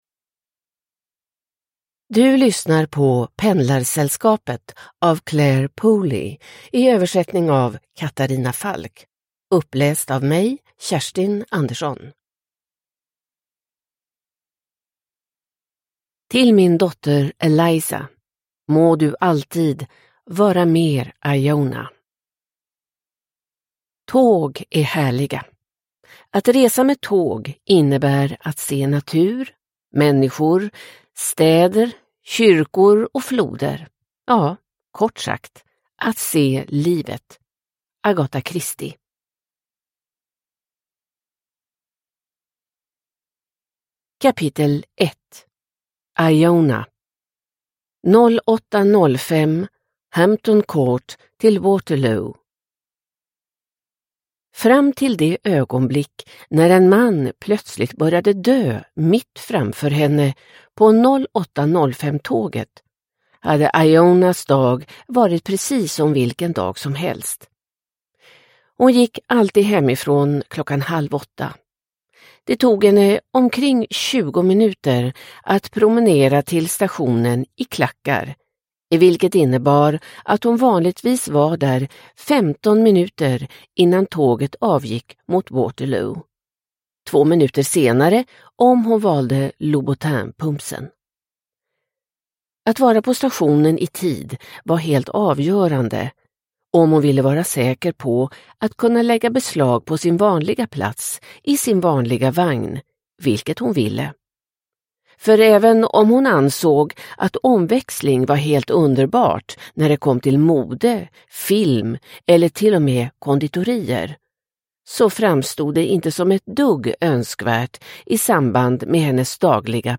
Pendlarsällskapet – Ljudbok – Laddas ner